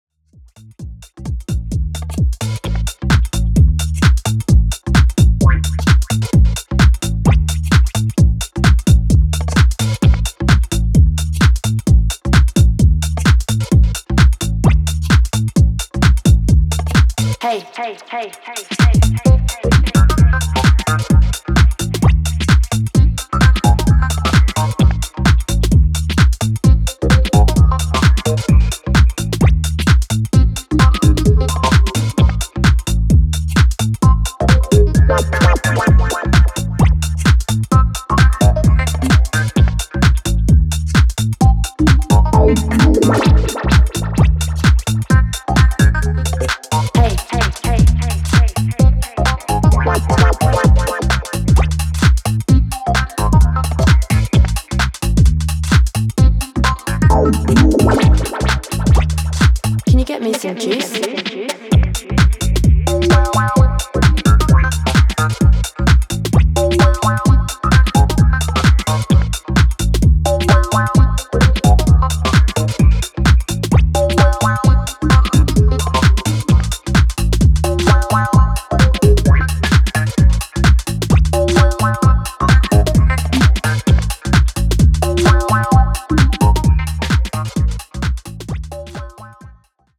軽快な足回りのテック・ハウス4曲を収録
広い音域でユーフォリックな躁状態をキープしつつも余白も忘れないミニマル・テック